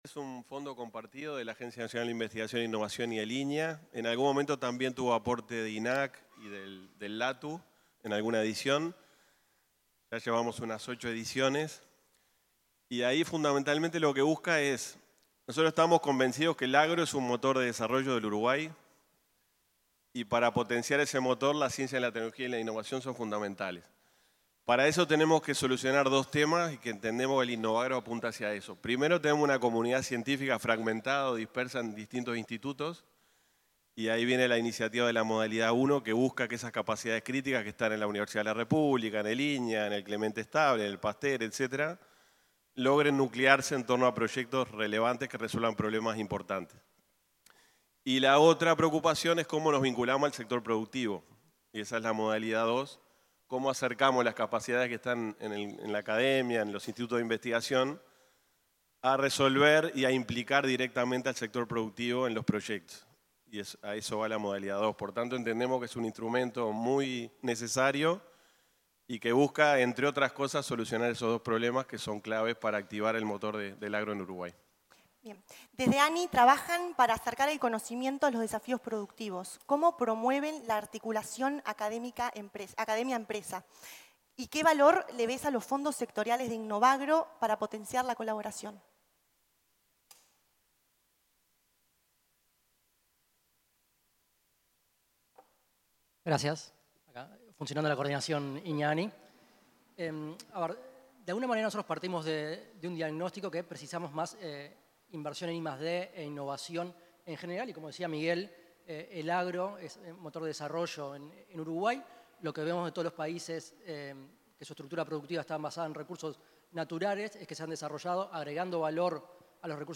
Palabras de titulares de ANII e INIA en presentación de proyectos del sector agropecuario y agroindustrial
Palabras de titulares de ANII e INIA en presentación de proyectos del sector agropecuario y agroindustrial 16/07/2025 Compartir Facebook X Copiar enlace WhatsApp LinkedIn Los presidentes de la Agencia Nacional de Investigación e Innovación (ANII), Álvaro Brunini, y el Instituto Nacional de Investigación Agropecuaria (INIA), Miguel Sierra, se expresaron en la presentación de proyectos del sector agropecuario y agroindustrial.